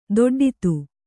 ♪ doḍḍitu